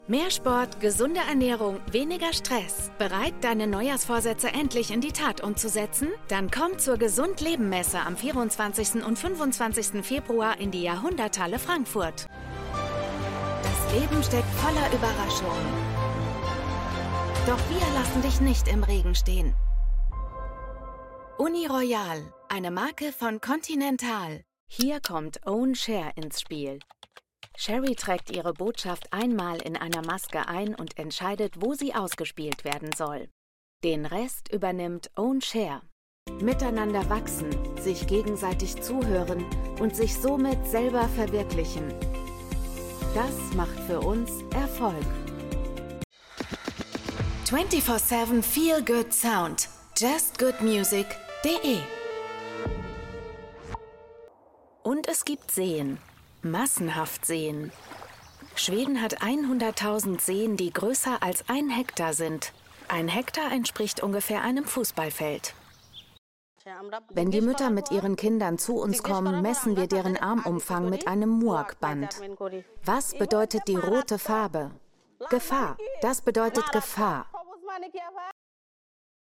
Female
Authoritative , Character , Confident , Corporate , Friendly , Natural , Reassuring , Smooth , Warm , Versatile